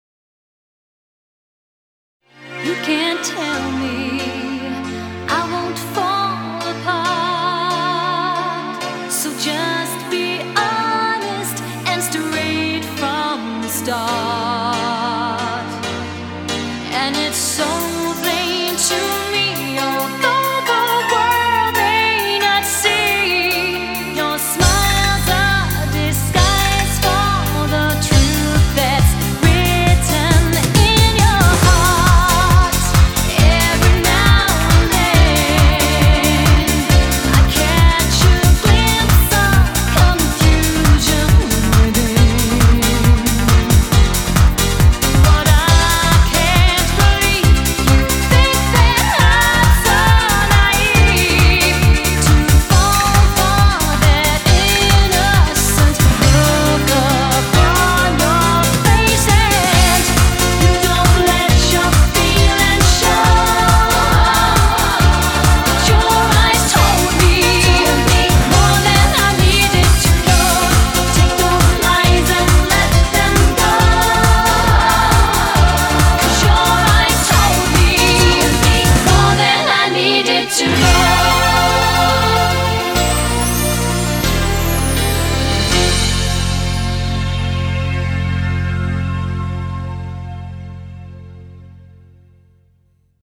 BPM136
Audio QualityPerfect (High Quality)